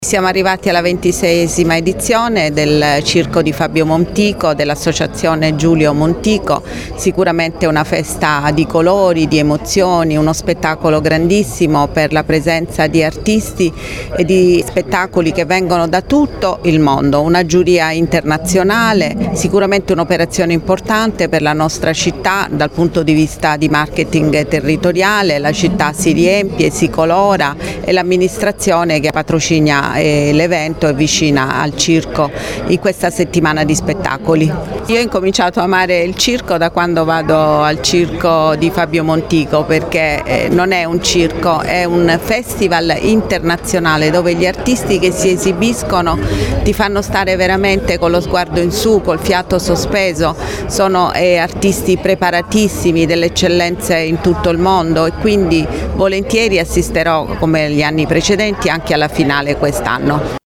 Latina ha ufficialmente acceso i riflettori sulla 26ª edizione del Festival Internazionale del Circo d’Italia, con la conferenza stampa di presentazione che si è svolta nella suggestiva cornice della Cittadella del Circo.
«Non sono mai stata una grande appassionata di circo, ma questo Festival mi ha fatto cambiare idea – ha detto la Sindaca Matilde Celentano: